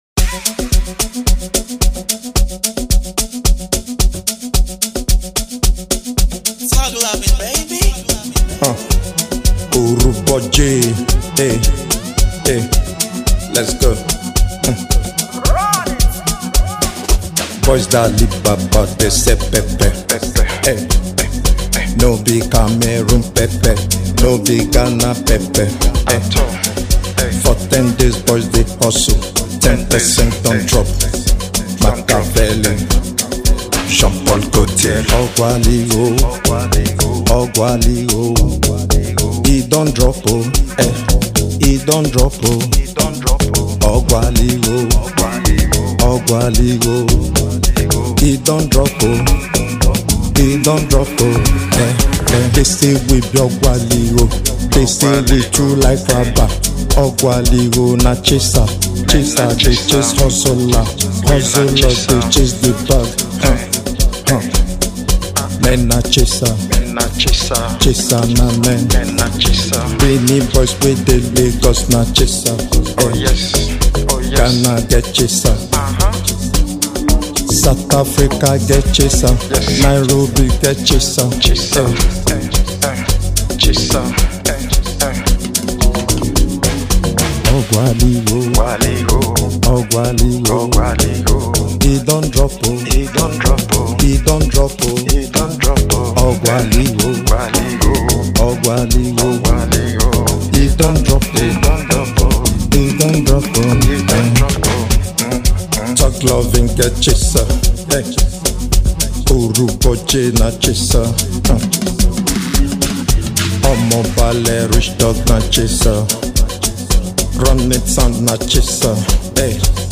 a uncooked and vibrant Afro-urban anthem